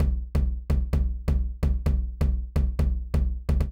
INT Beat - Mix 12.wav